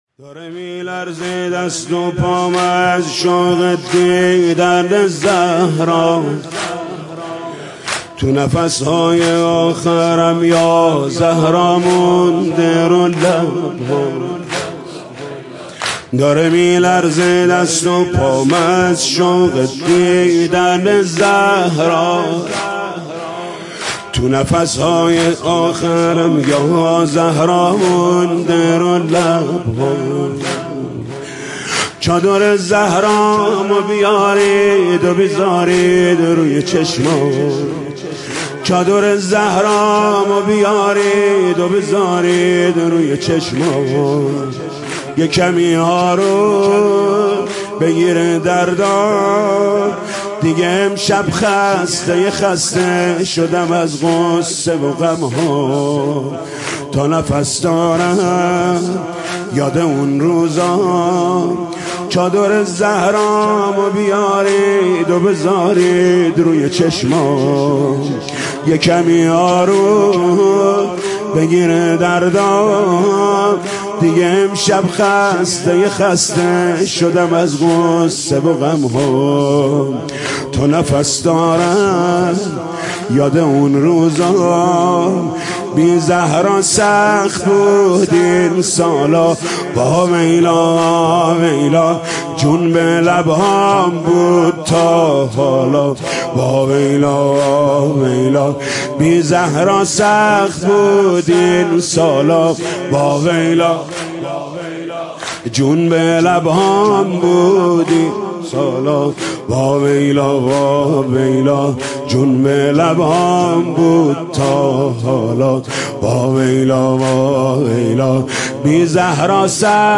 مداحی جدید
شب بیستم رمضان ۹۷